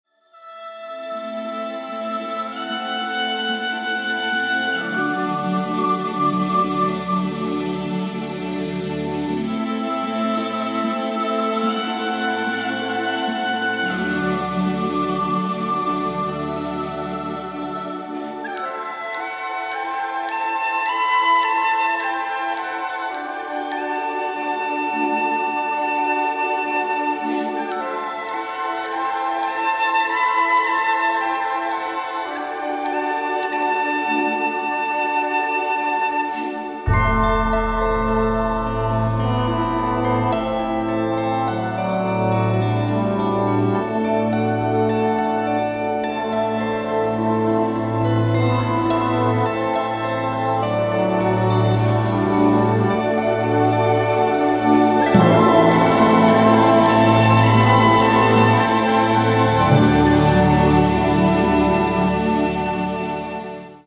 Каталог -> Поп (Легкая) -> Клубная